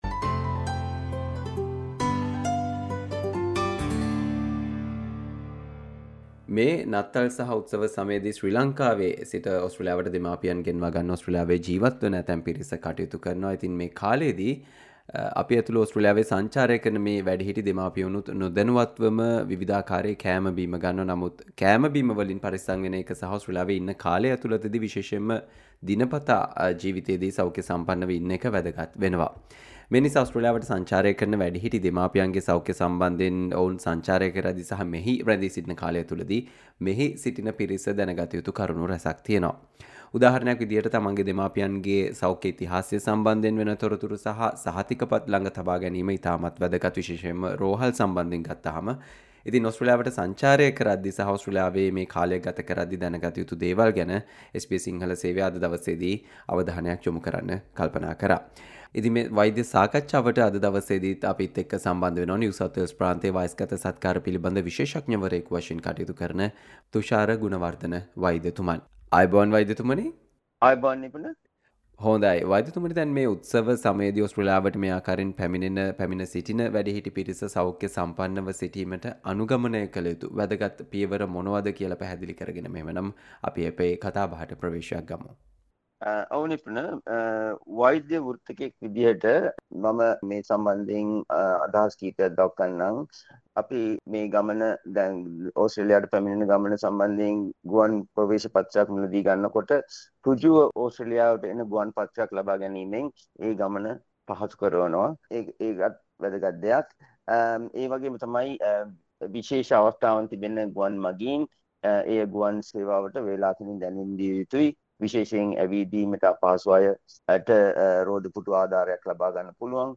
Listen to the SBS Sinhala discussion on How to take care of elderly people traveling from Sri Lanka to Australia during this Christmas season